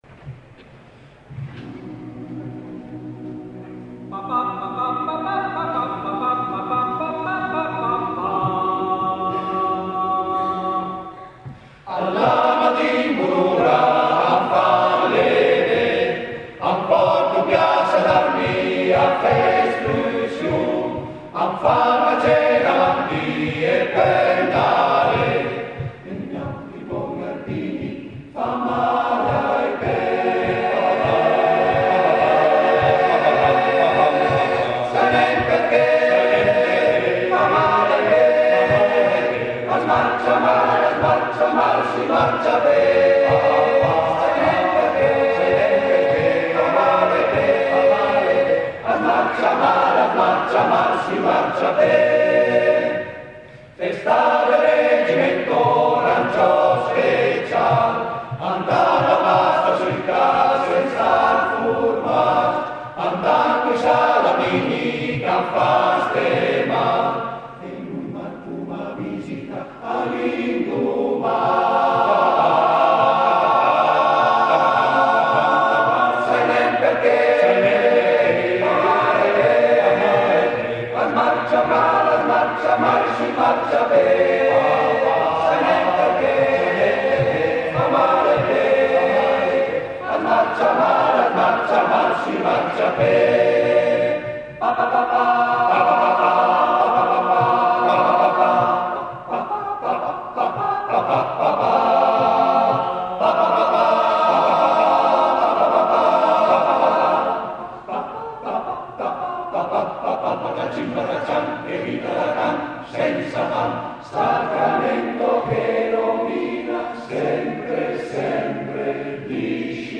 Esecutore: Coro Edelweiss
Fa parte di: Concerto Conservatorio 150° CAI / Coro Edelweiss